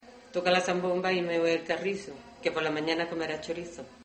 Materia / geográfico / evento: Villancicos Icono con lupa
Alhama de Granada Icono con lupa
Secciones - Biblioteca de Voces - Cultura oral